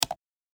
ボタン・システム （87件）
マウスクリック.mp3